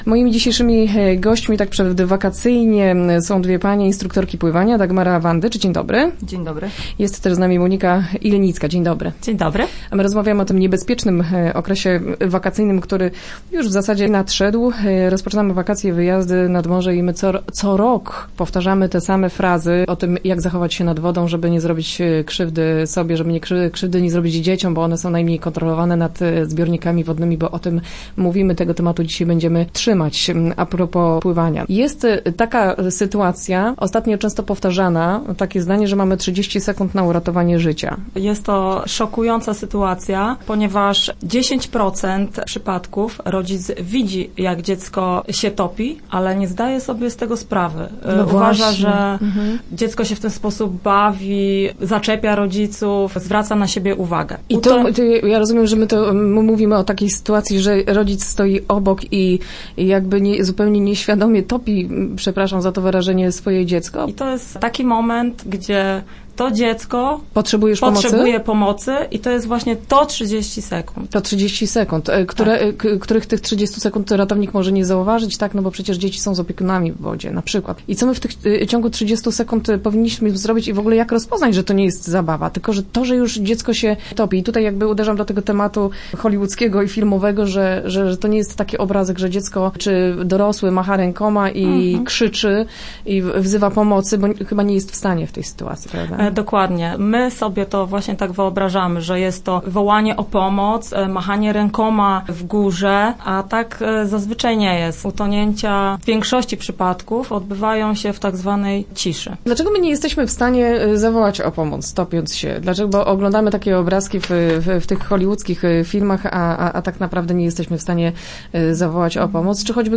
Start arrow Rozmowy Elki arrow Nie bój się uderzyć, tak możesz uratować życie!